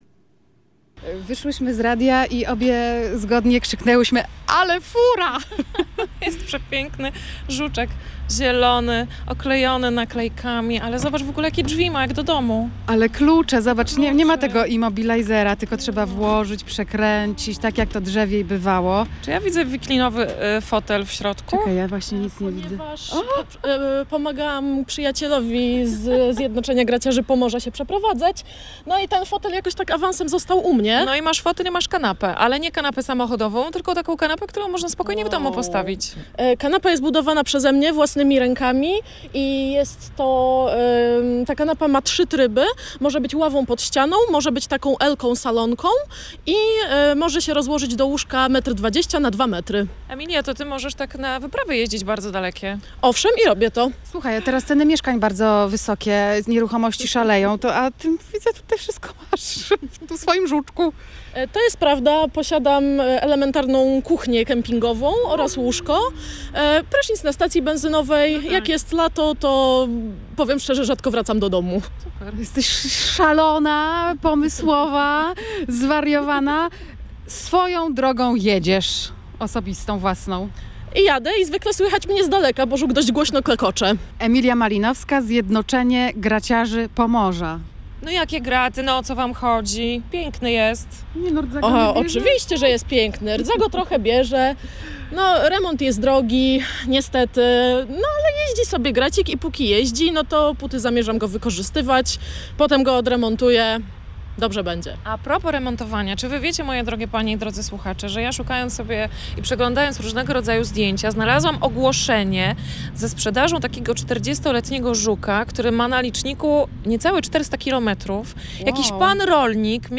Gdy przemierza trójmiejskie ulice, nie sposób nie zwrócić na niego uwagi. Jest duży, głośny i wywołuje masę wspomnień – w końcu to klasyczny polski żuk.